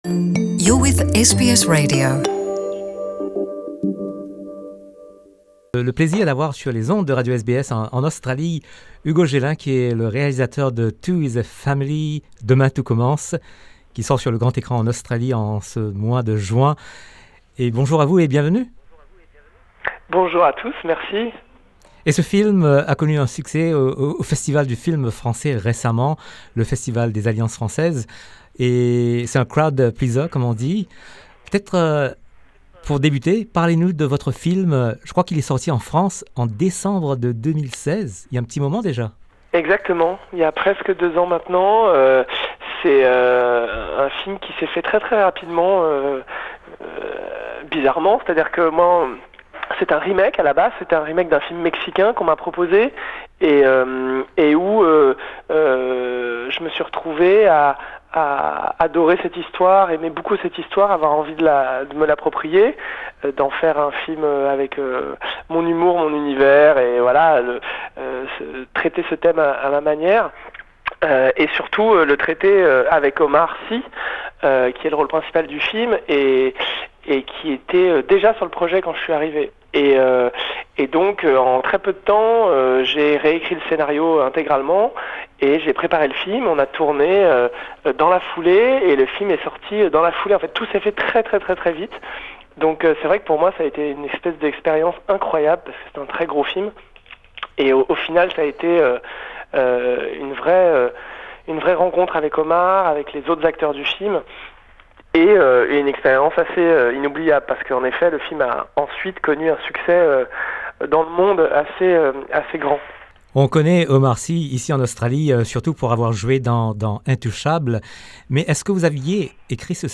Nous sommes en ligne avec Hugo Gélin, réalisateur du film ‘Two is a Family’ qui sort dans les salles en Australie en ce mois de juin.